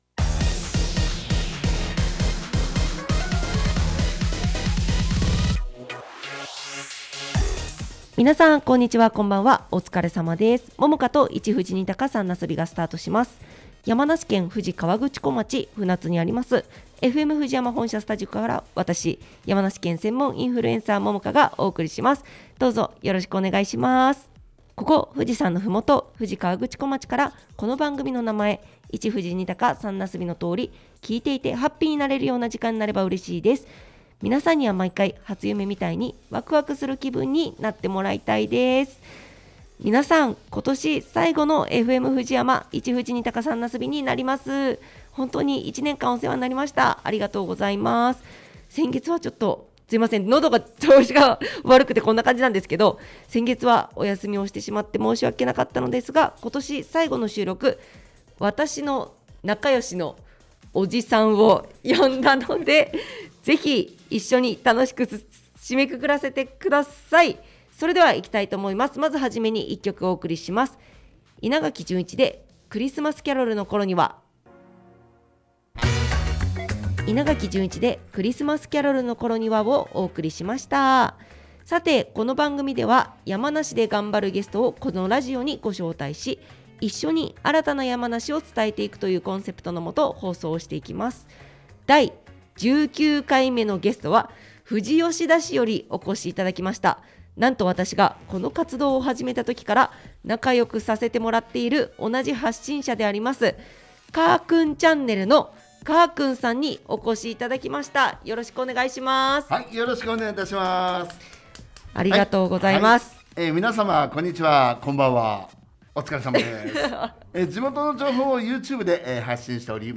（ネット配信の為楽曲はカットしています）